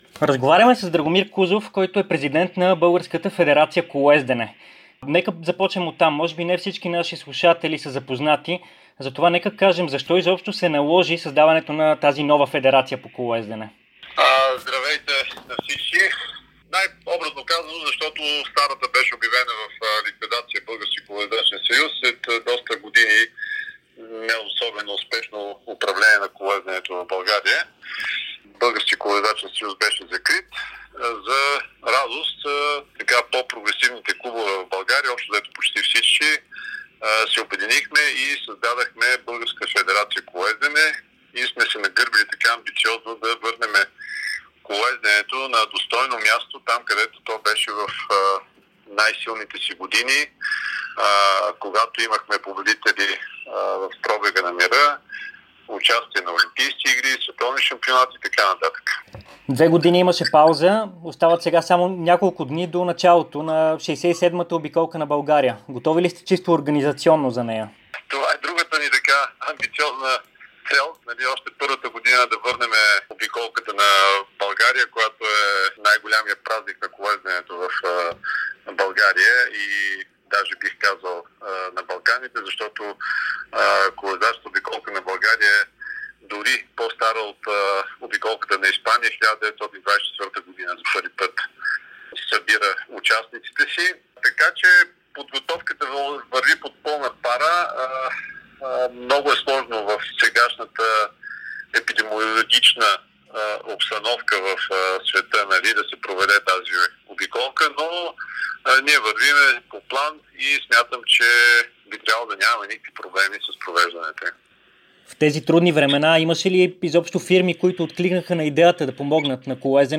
специално интервю за dsport и Дарик радио